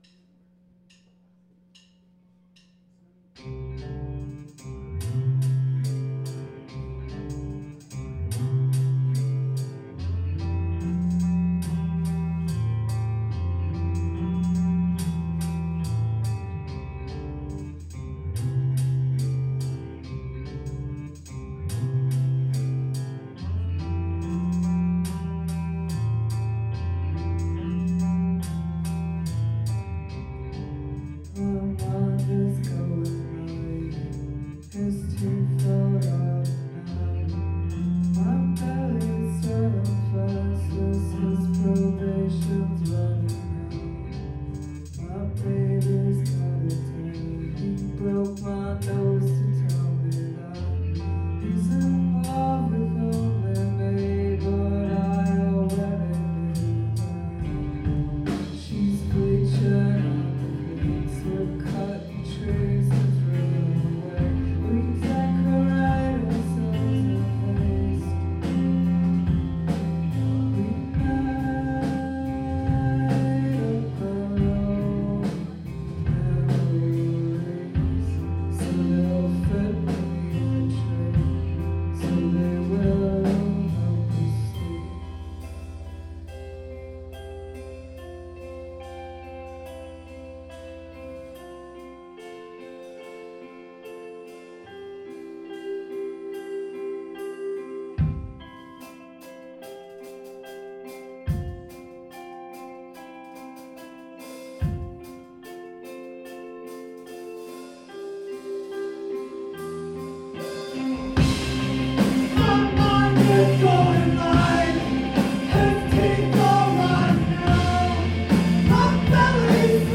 2006-09-09 The Paradox Theatre – Seattle, WA